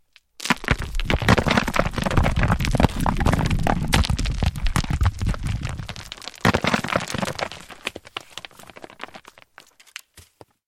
На этой странице собраны звуки камнепада — от легкого шелеста скатывающихся камешков до грохота крупных обвалов.
Звук камнепада по горным склонам